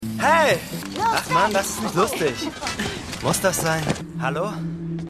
Synchronstudio: Arena-Synchron [Berlin]